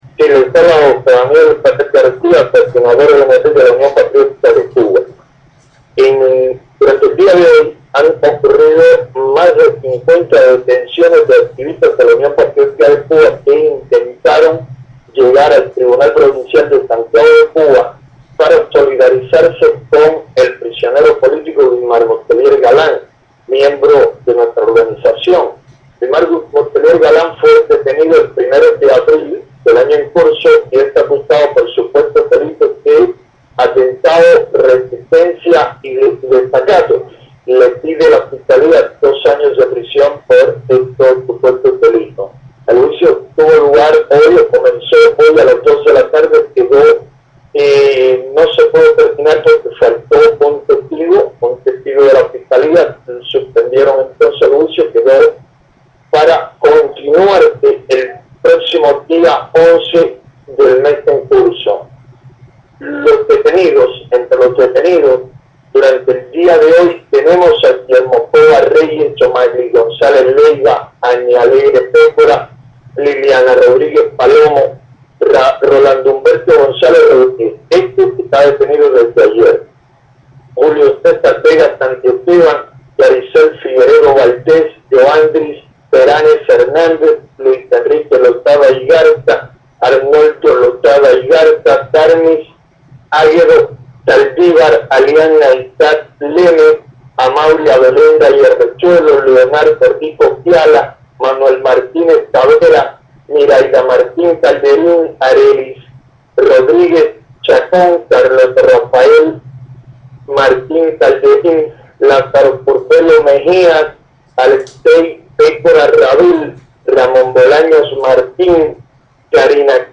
José Daniel Ferrer habla para el OCDH sobre los arrestos de última hora en Santiago de Cuba